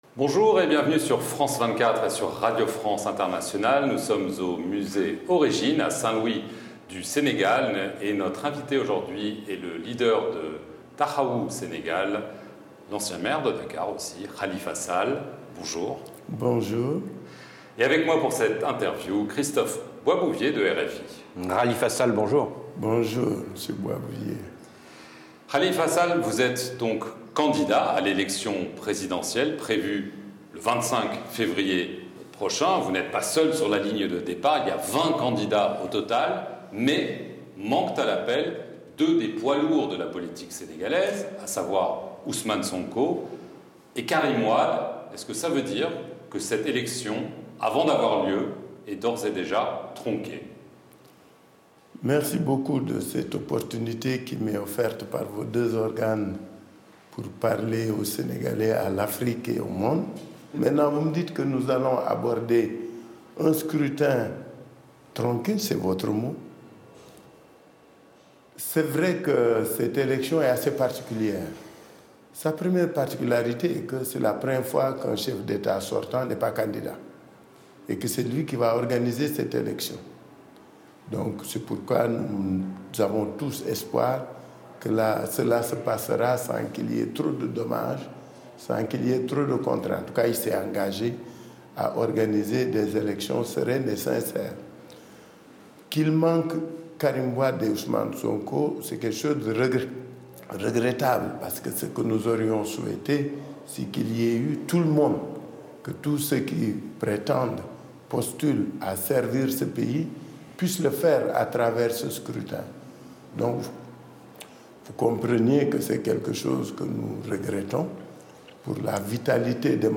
L'Entretien